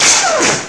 sparkShoot2.ogg